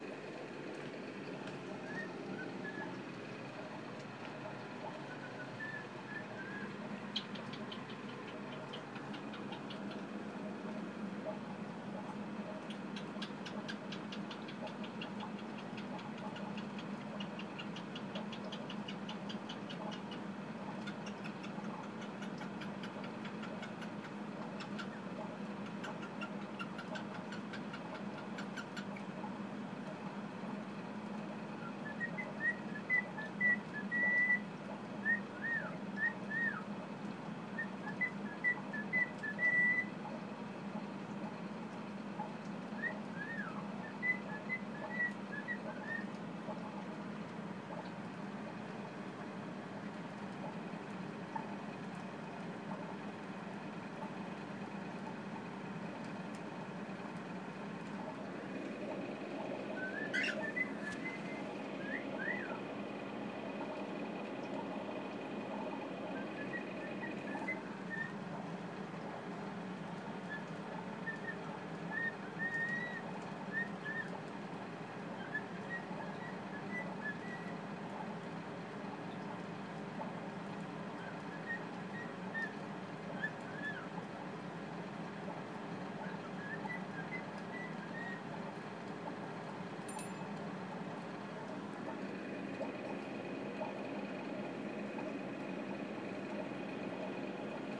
Marvin the cockatiel singing